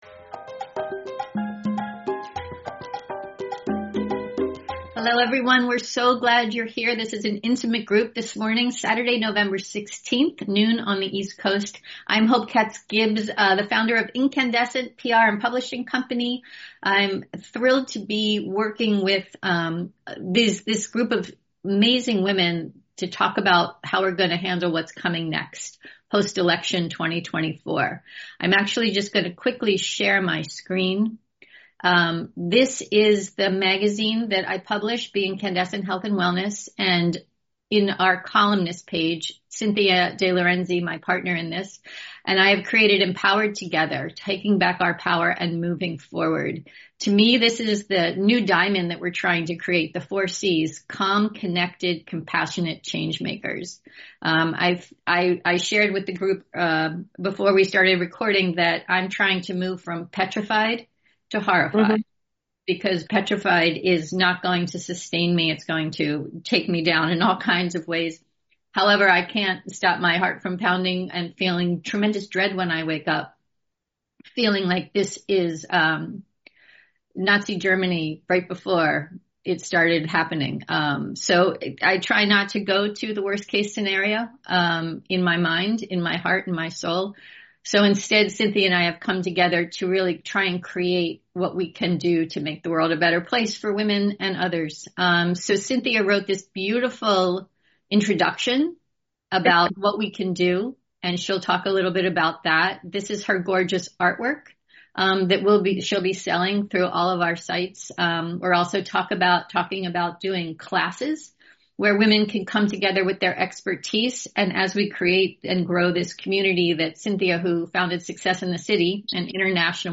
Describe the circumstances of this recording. To start us off, we hosted our first Zoom Mastermind meeting on Saturday, Nov. 16.